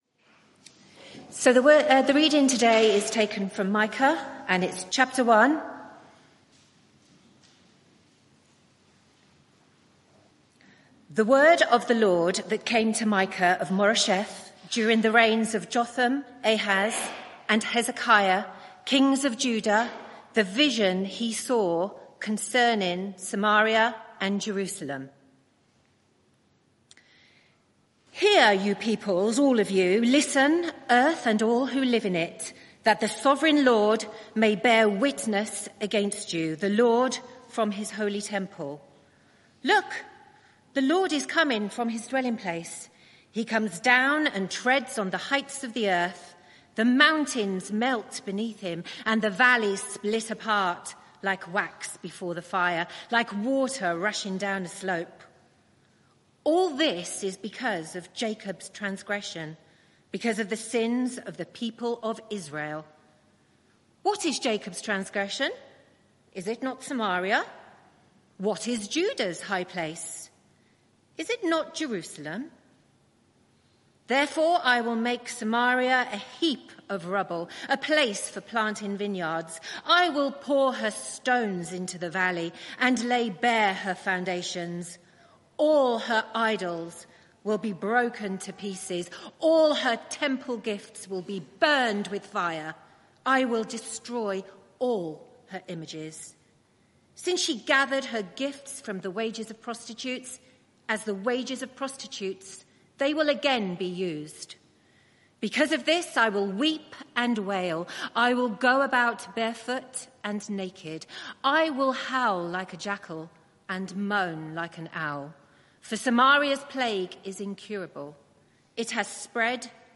Media for 11am Service on Sun 25th May 2025 11:00 Speaker
Who is like the Lord our God? Theme: Idolatry Exposed There is private media available for this event, please log in. Sermon (audio) Search the media library There are recordings here going back several years.